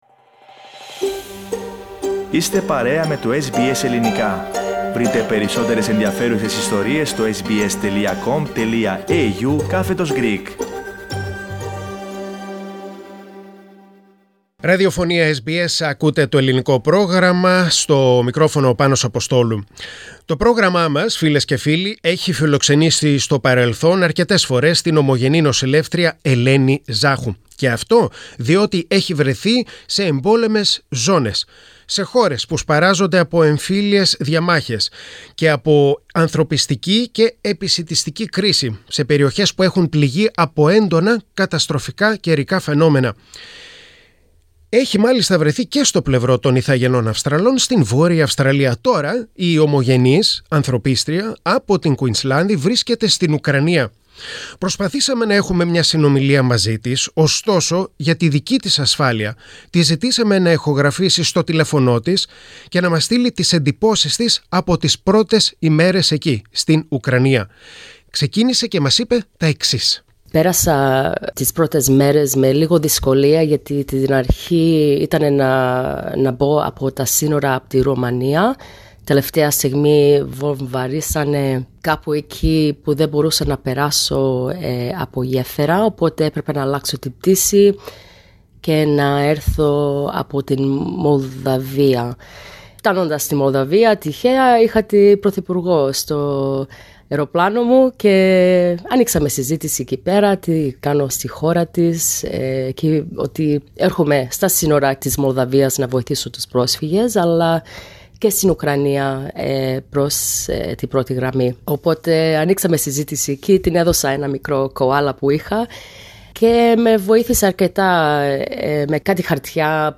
Προσπαθήσαμε να έχουμε μια συνομιλία μαζί της, ωστόσο για την δική της ασφάλεια, της ζητήσαμε να ηχογραφήσει στο τηλέφωνό της και να μας στείλει τις εντυπώσεις της από τις πρώτες ημέρες εκεί.